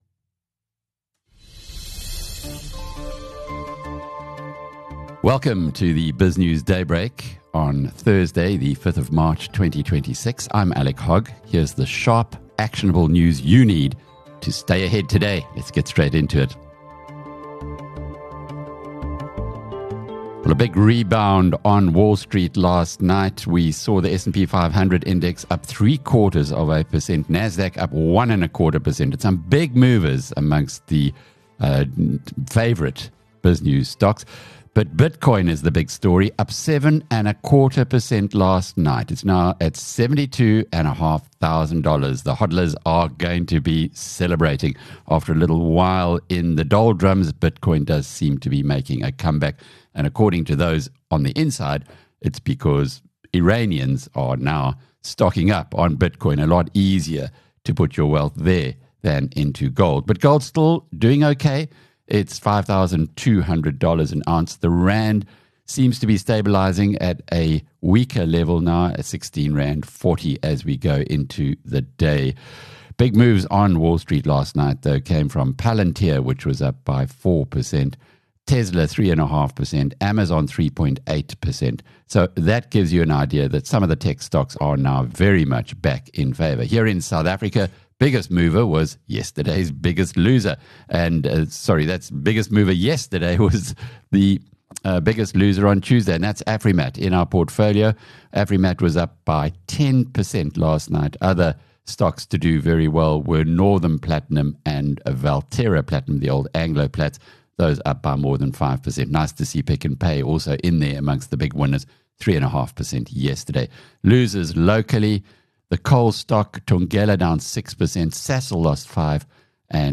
Welcome to BizNews Daybreak with Alec Hogg—your essential morning update for Thursday, 5 March 2026.